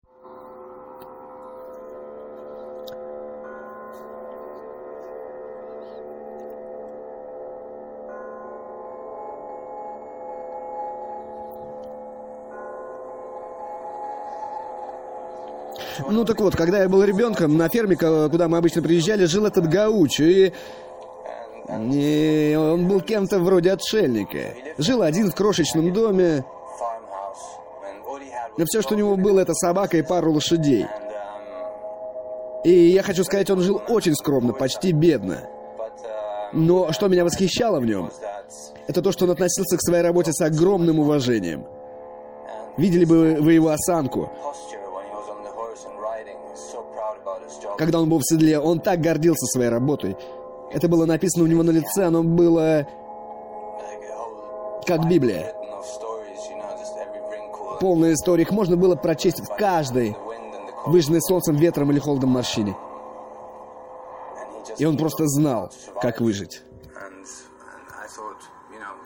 актерская визитка